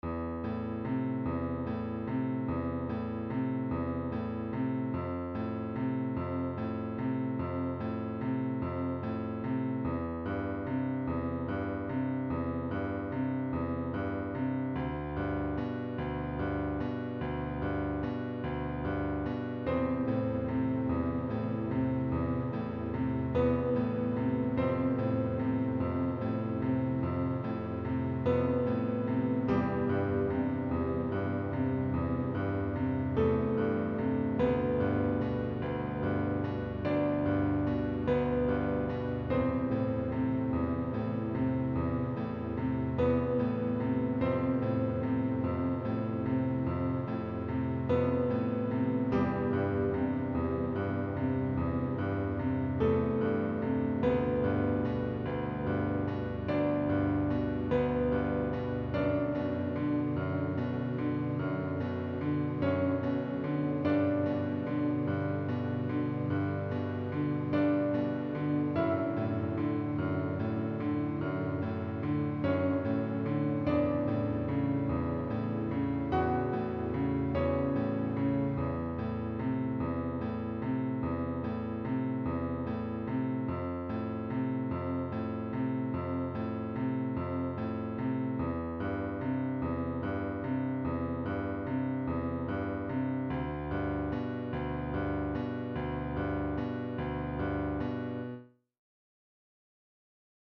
piano shite
not that great quality. not a full song in the group.
piano_long_199.mp3